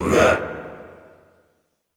Index of /90_sSampleCDs/Best Service - Extended Classical Choir/Partition I/DEEP SHOUTS
DEEP UAE 1-L.wav